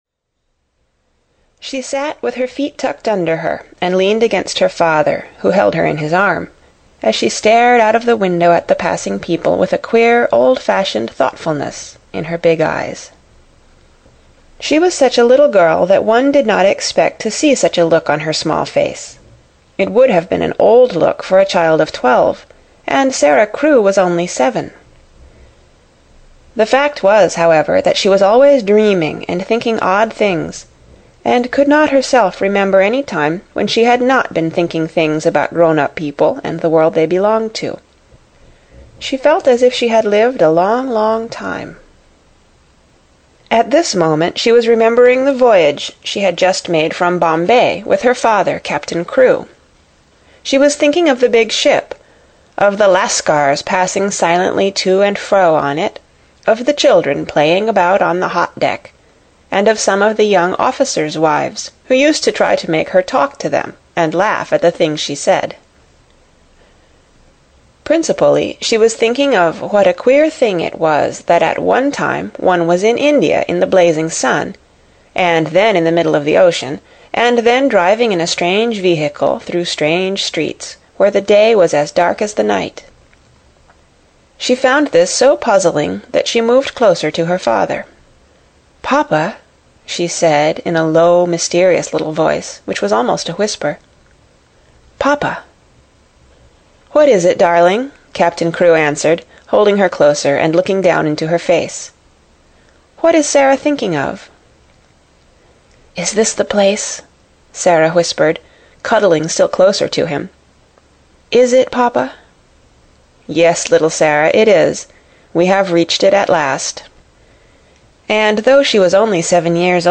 A Little Princess (EN) audiokniha
Ukázka z knihy